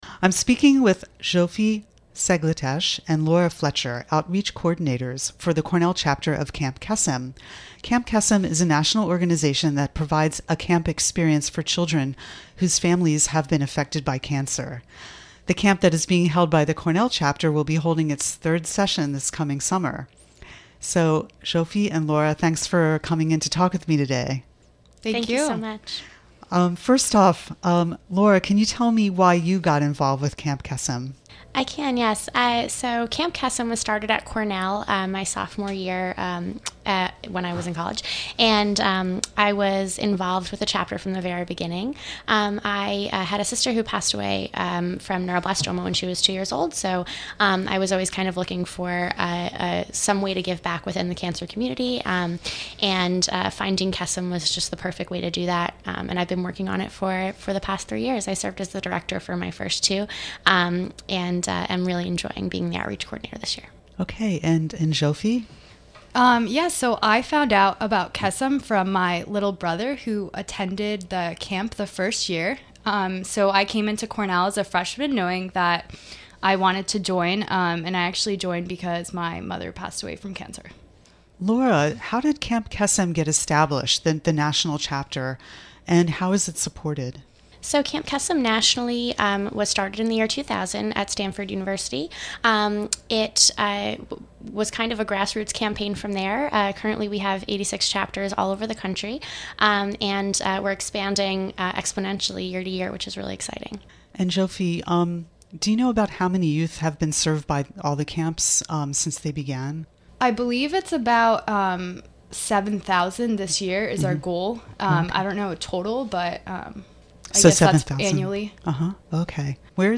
This interview originally aired on the May 8th, 2017 edition of WRFI Community Radio News.